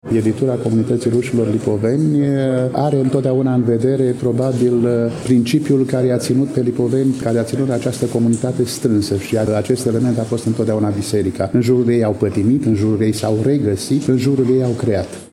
În ediția de astăzi a emisiunii noastre, relatăm de la prezentarea cărții Marele Inchizitor. Dostoievski în interpretări teologico-filosofice (Konstantin Leontiev, Vladimir Soloviov, Vasili Rozanov, Serghei Bulgakov, Nikolai Berdiaev, Dmitri Merejkovski, Semion Frank, Nikolai Losski), eveniment desfășurat în ziua de joi, 14 noiembrie 2024, începând cu ora 14,  la Iași, în incinta Librăria Tafrali, corpul A al Universității „Alexandru Ioan Cuza”.